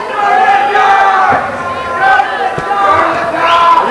May 23, 1996 - Dunwoody High School's "Battle of the Bands"
Go to the docks!!" as well as "Freebird!!" echoed throughout the night as power chords ripped through cover songs, and band after band took the stage.